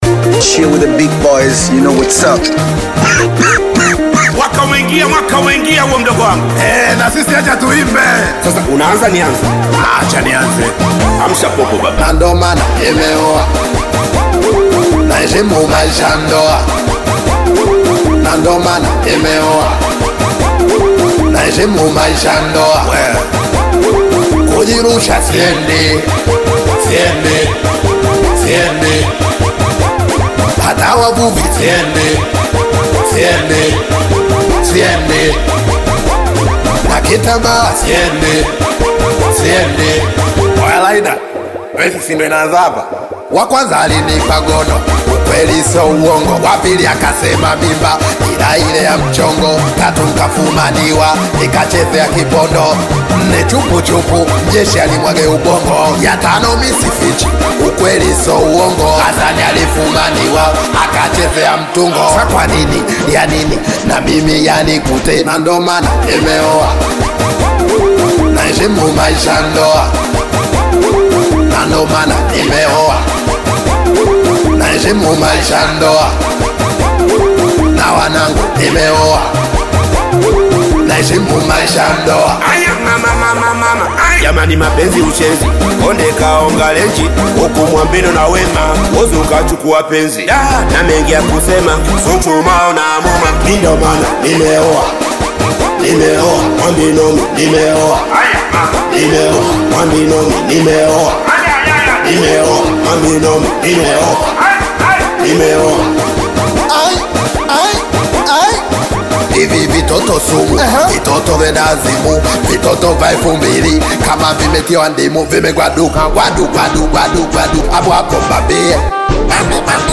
soulful Bongo Flava single
Genre: Singeli